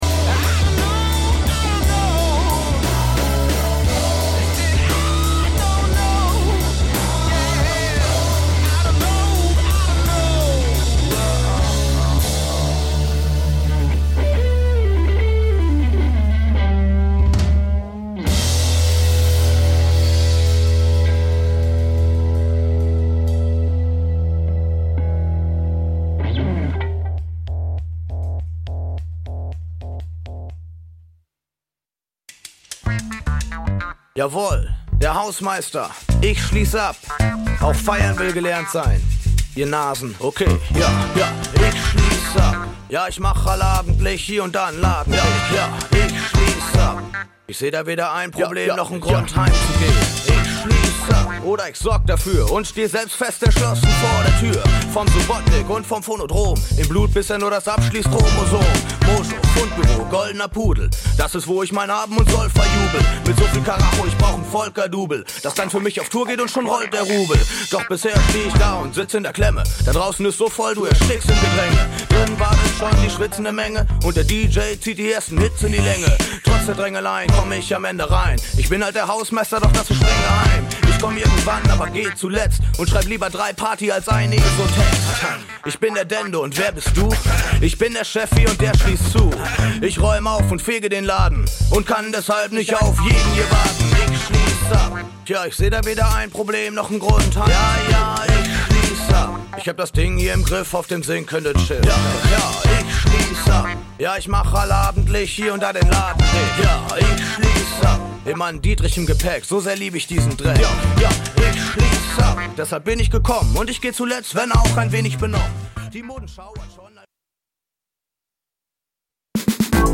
Soulmusik Dein Browser kann kein HTML5-Audio.